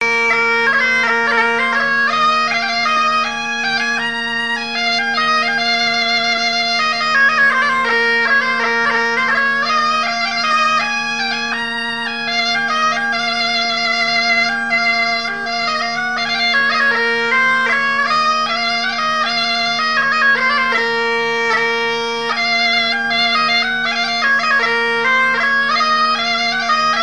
Genre strophique
Airs joués à la veuze et au violon
Pièce musicale inédite